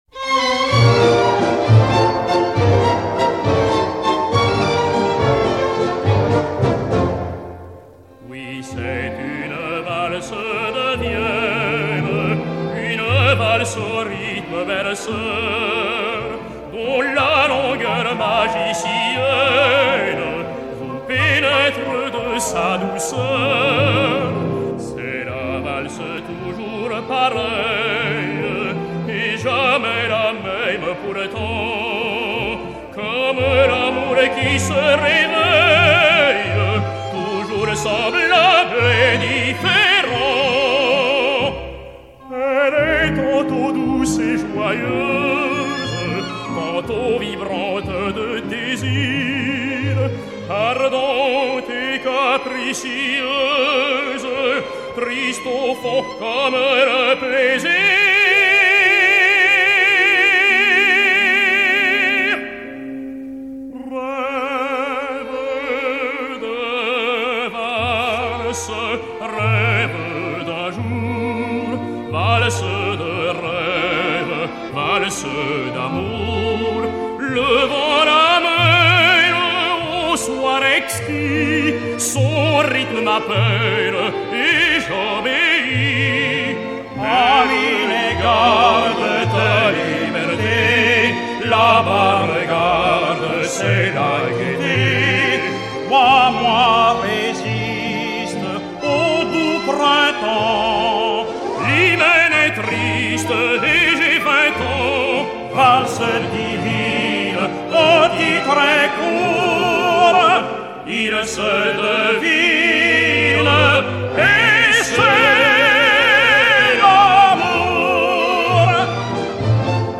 Duo-valse